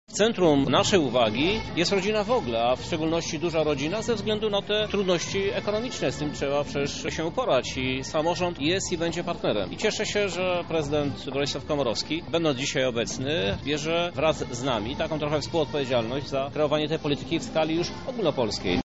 Staramy się wspierać duże rodziny i być dla nich partnerem – mówi Krzysztof Żuk prezydent Lublina